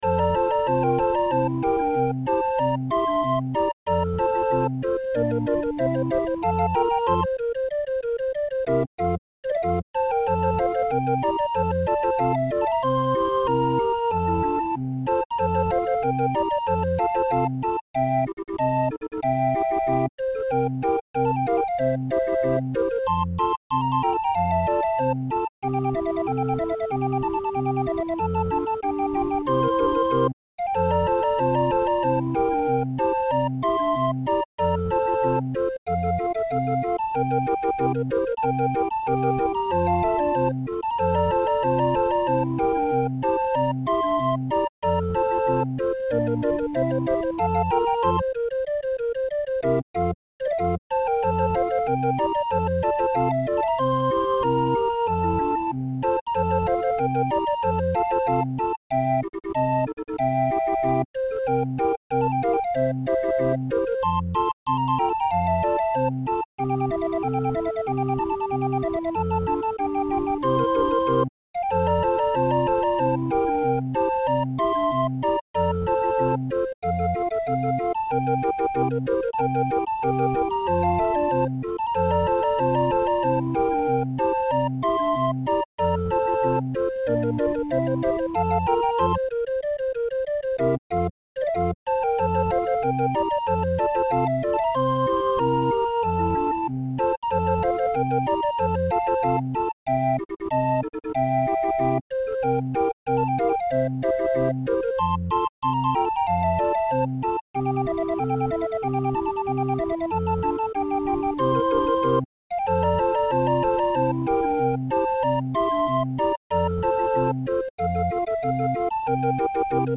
Muziekrol voor Raffin 20-er